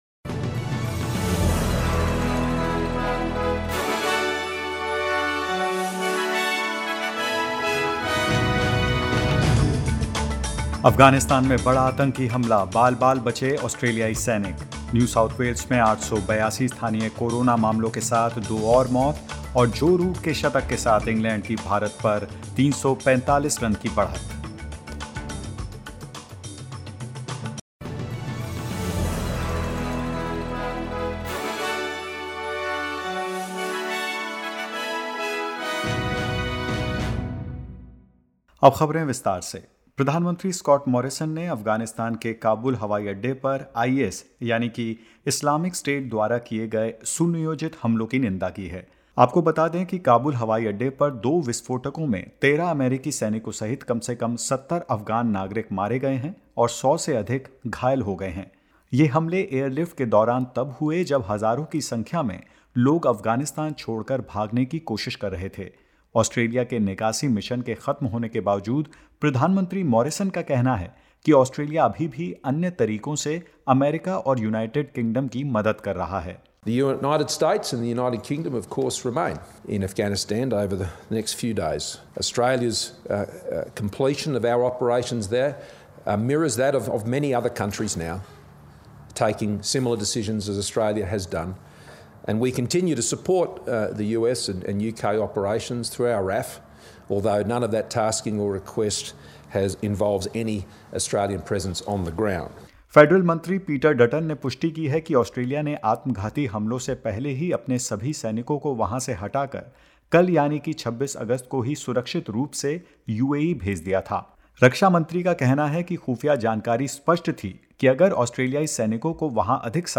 In this latest SBS Hindi News bulletin of Australia and India: Victoria records 79 new locally acquired cases, with 53 linked to known outbreaks and 26 mystery cases; ACT records 21 new locally acquired cases and more.